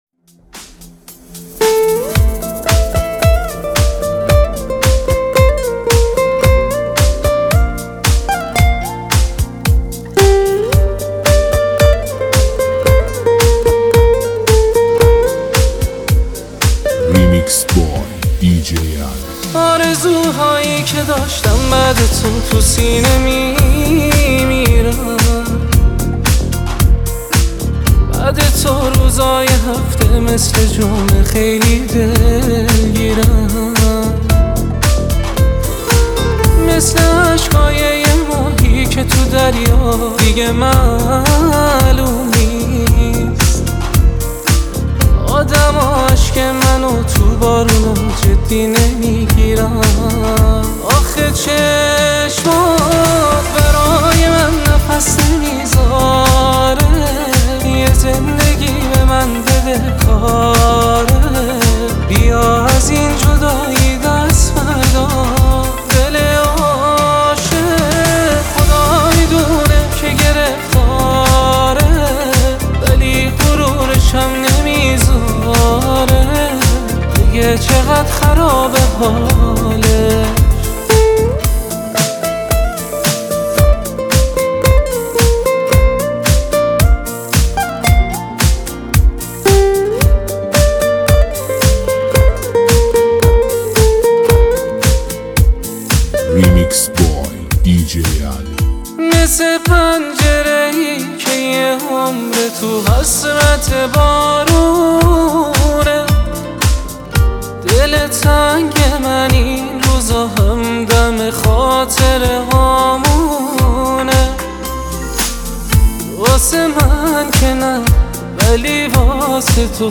بیس دار MP3heheshmat